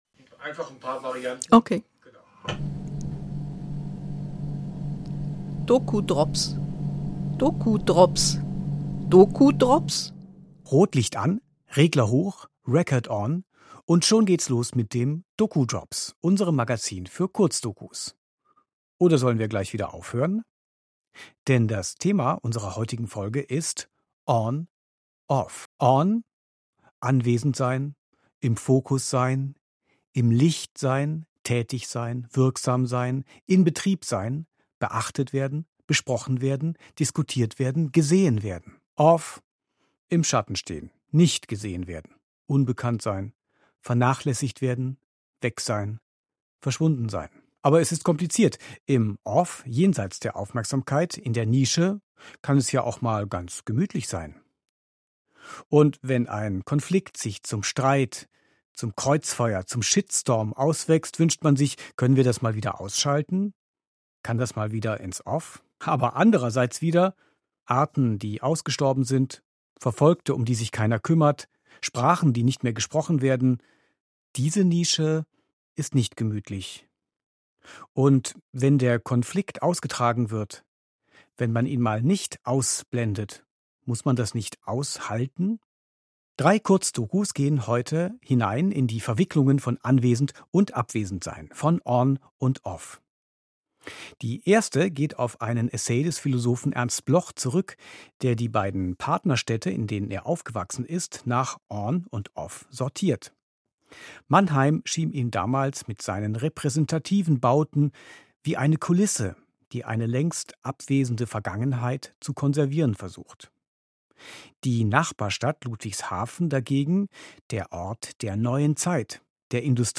Feature Jeden Monat neue Kurzdokus doku drops 10: On/Off 27:15 Minuten Monatlich neu: die doku drops.